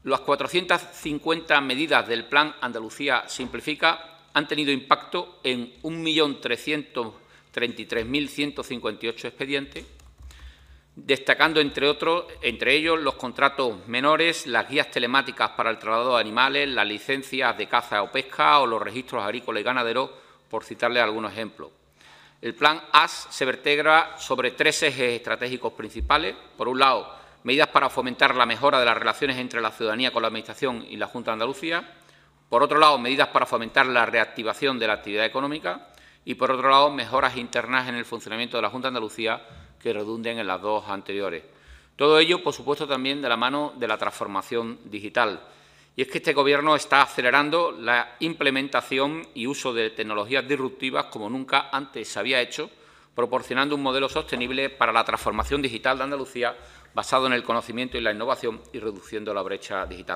El consejero de la Presidencia, Interior, Diálogo Social y Simplificación Administrativa, Antonio Sanz, ha dado a conocer datos en materia de simplificación administrativa durante su comparecencia de hoy en la Comisión del Parlamento de Andalucía.